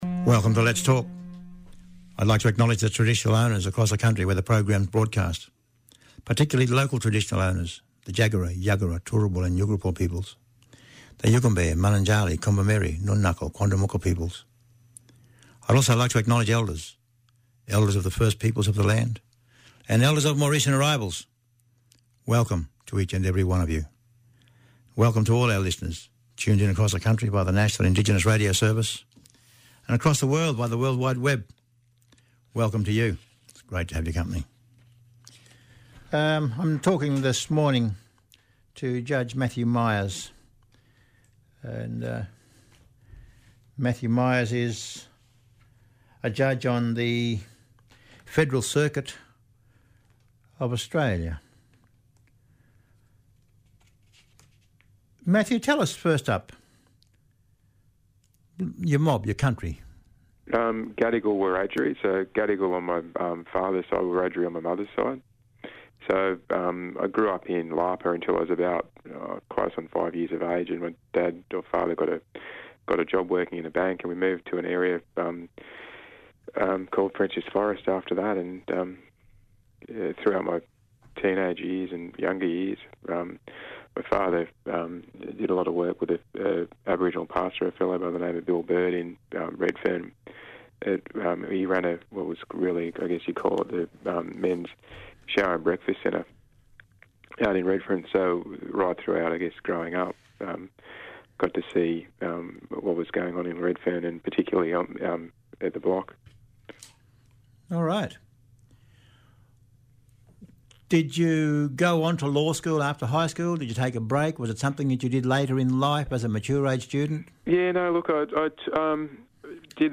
Judge of the Federal Circuit Court speaking about the launch of the courts Reconciliation Action Plan for 2014-2016